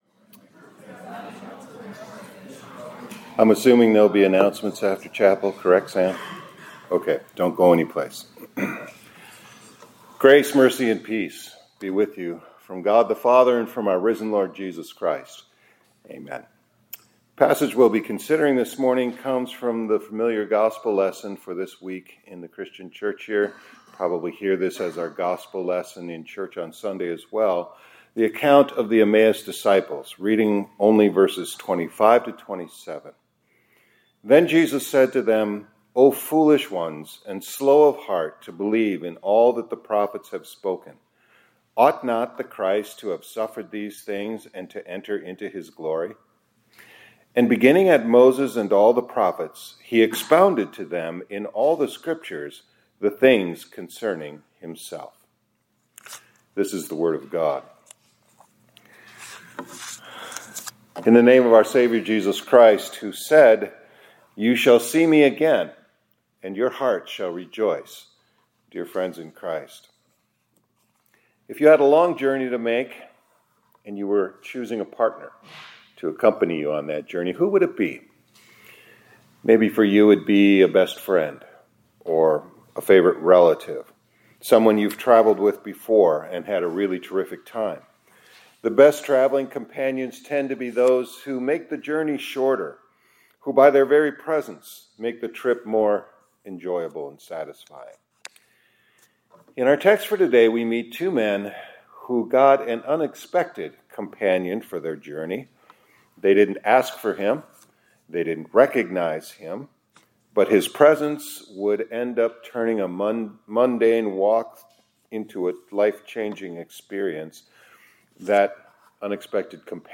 2026-04-16 ILC Chapel — The Perfect Partner for the Path Ahead